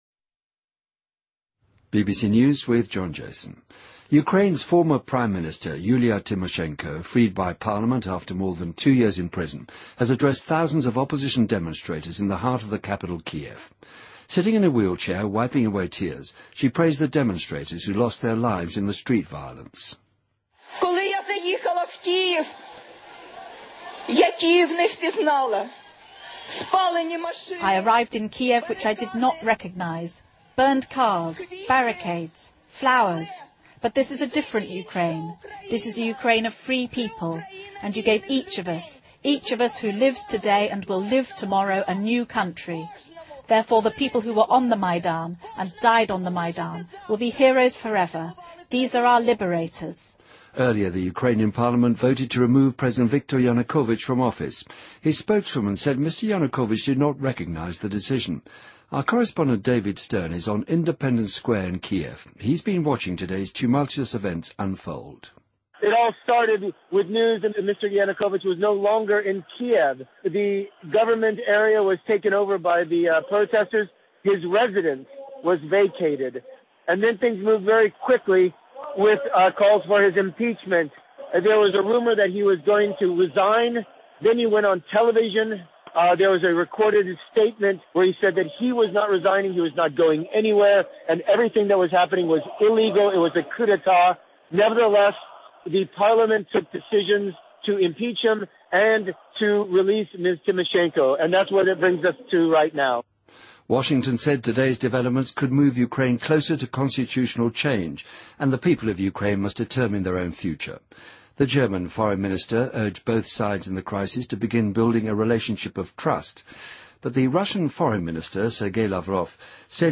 BBC news:2014-02-23|BBC在线收听